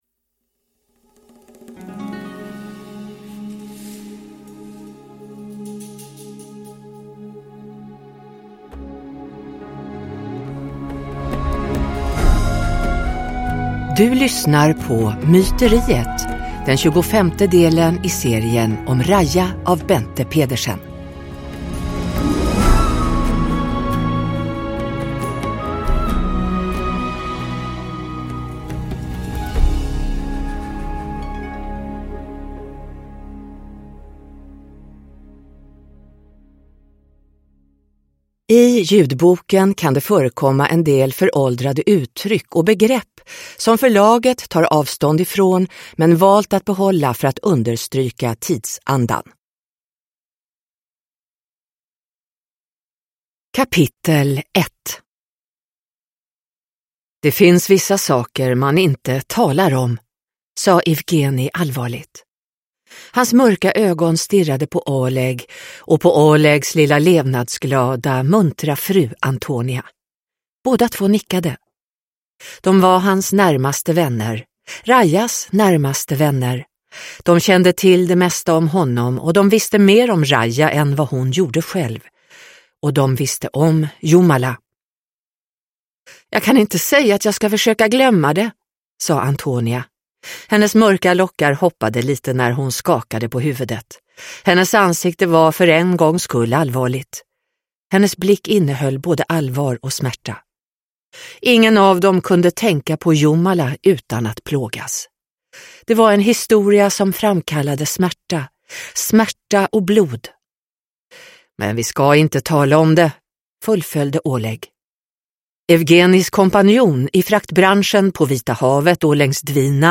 Myteriet – Ljudbok